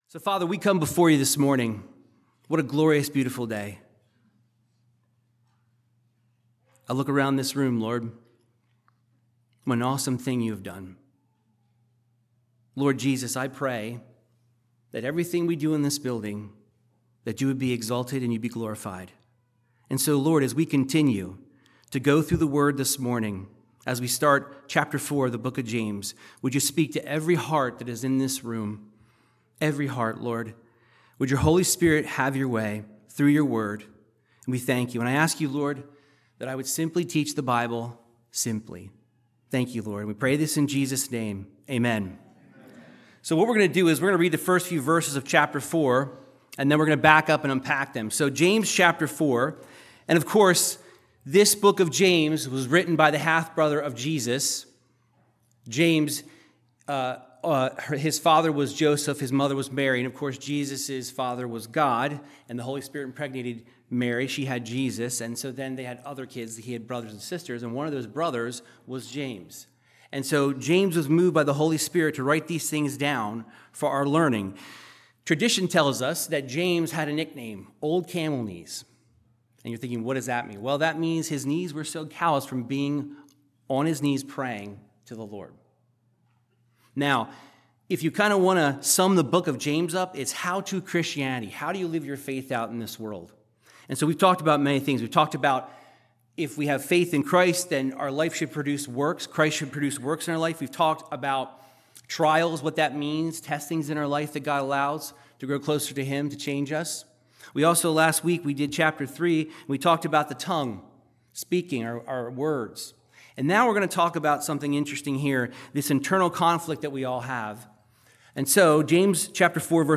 Verse By Verse Bible Teaching from James 4:1-10 discussing our need to be a friend of God instead of the world and how God enables us to do so